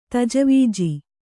♪ tajavīji